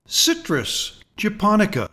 Pronounciation:
CI-trus ja-PON-i-ka